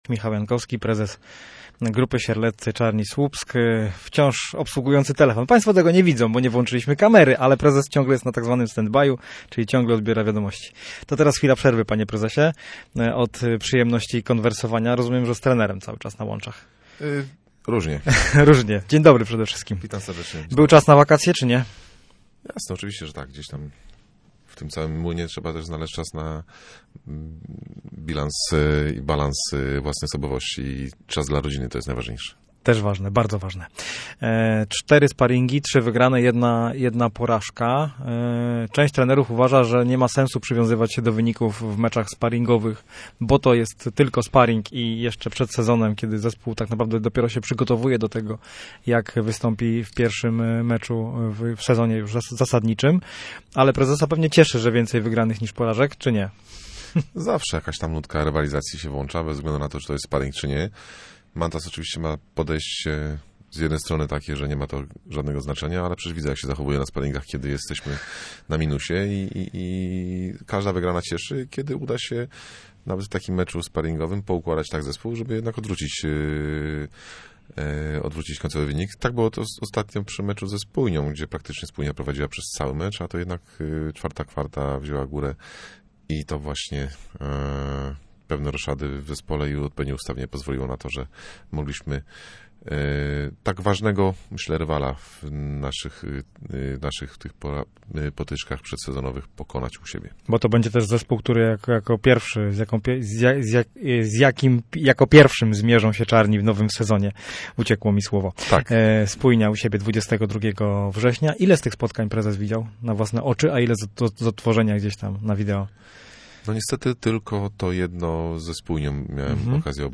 gościem miejskiego programu Radia Gdańsk Studio Słupsk 102 FM